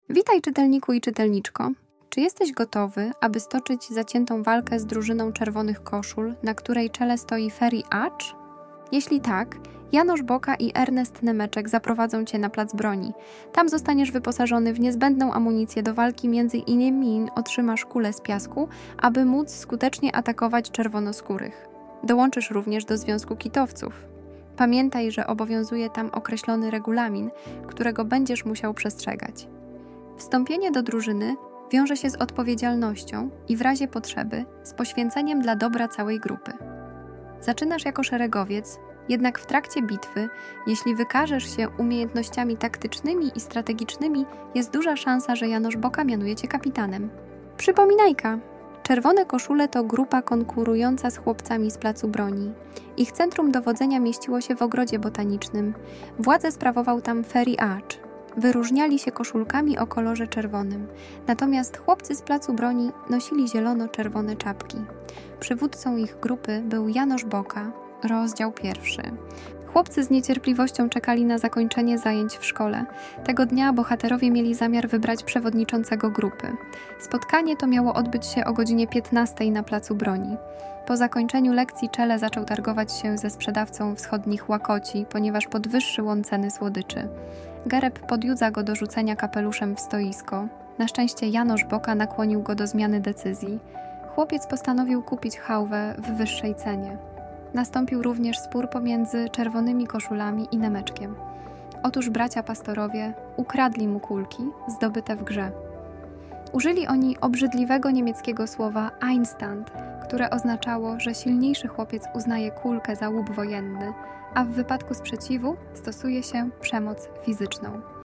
Chłopcy z Placu Broni - Prezentacja multimedialna, audiobook, e -book